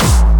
VEC3 Bassdrums Dirty 23.wav